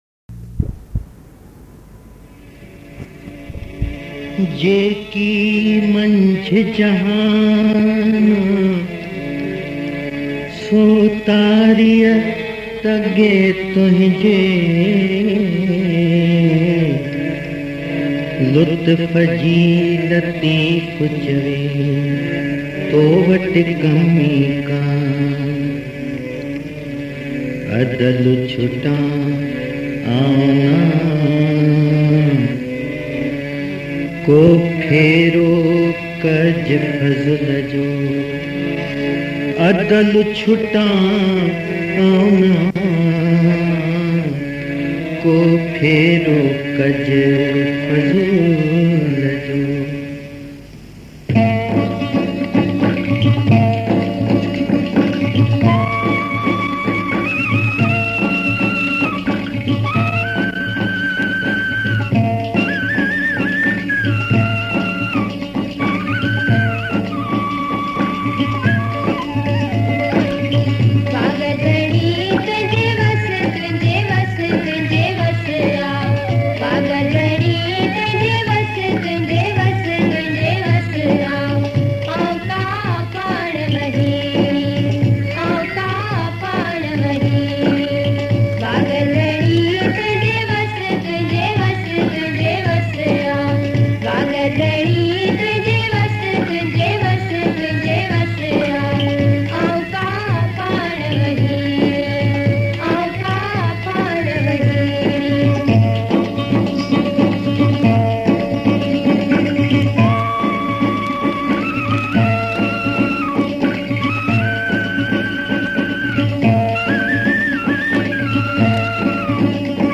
Sindhi Geet ain Kalam. Classical songs